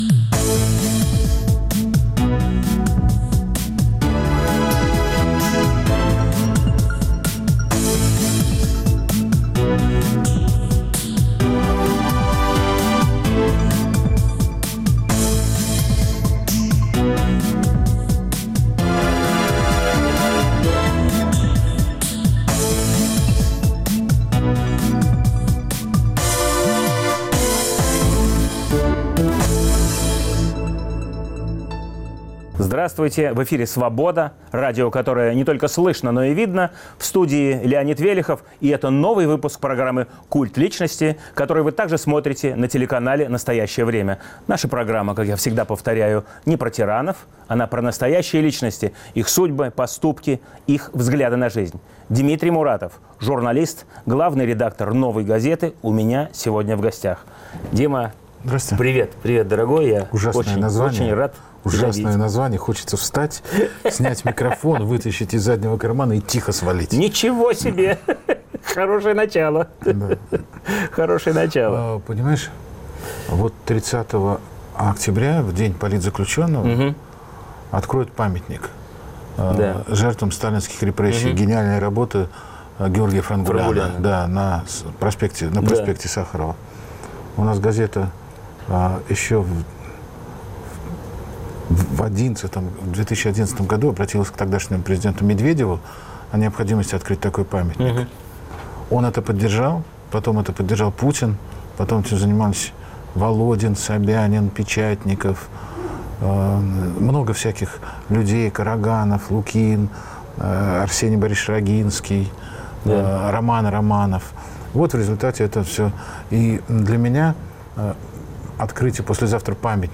В студии нового выпуска "Культа личности" главный редактор "Новой газеты" Дмитрий Муратов.